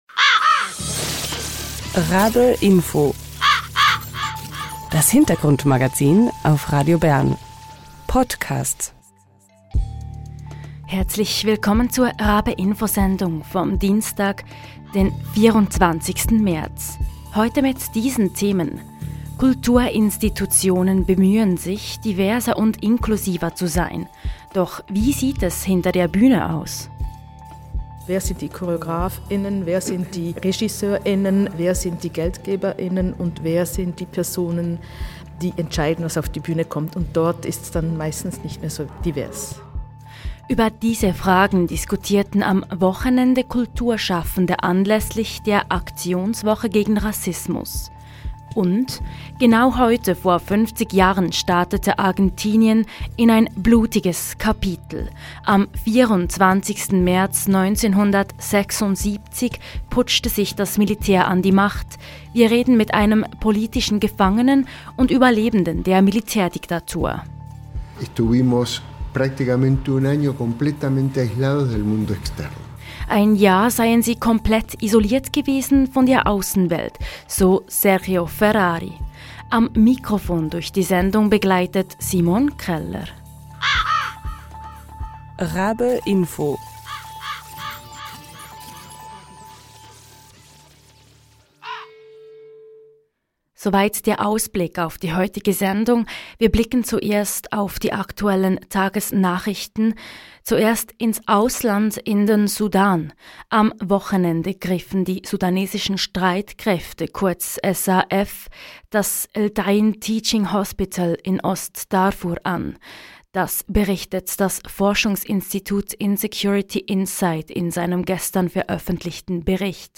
Genau heute vor 50 Jahren startete Argentinien in ein blutiges Kapitel: Am 24. März 1976 putschte sich das Militär an die Macht. Wir reden mit einem politischen Gefangenen und Überlebenden der Militärdiktatur